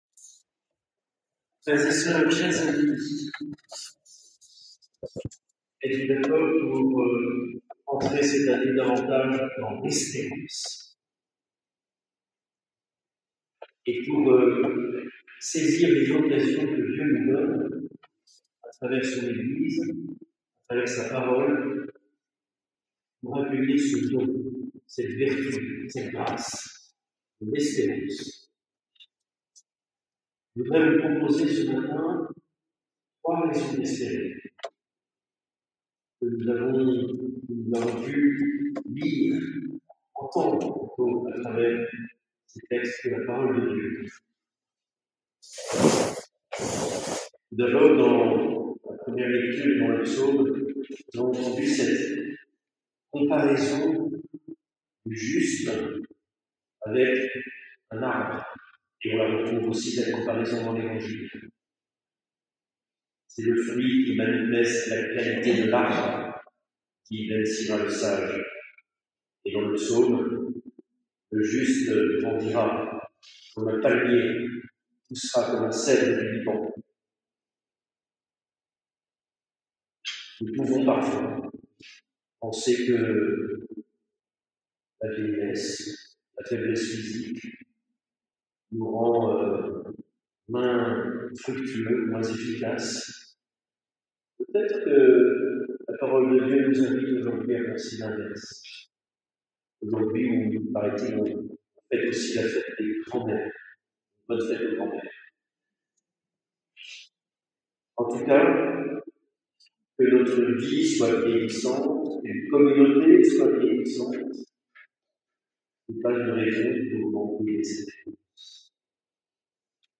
Homélie :